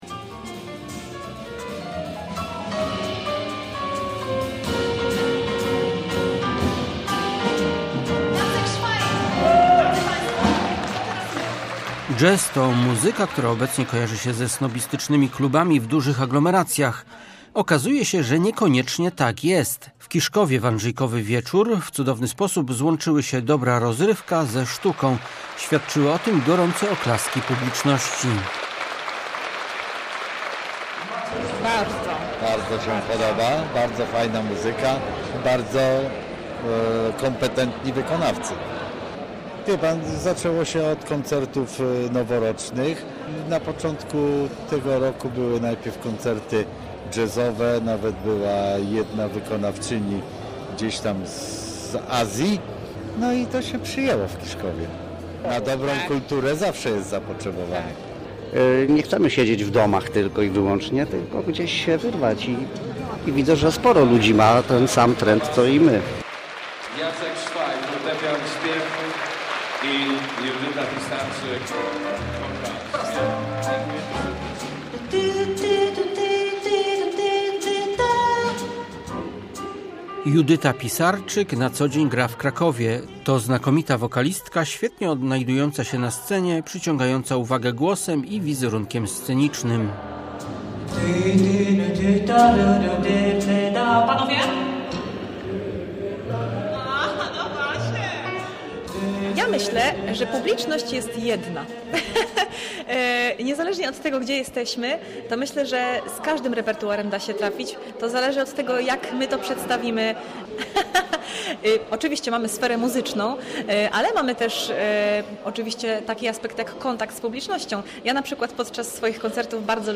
Na widowni w hali widowiskowo - sportowej zasiadł co dziesiąty mieszkaniec gminy położonej pomiędzy Gnieznem, a Murowaną Gośliną.
Na scenie w Kiszkowie wystąpili artyści z Kuby, Ukrainy i oczywiście polscy jazzmanii.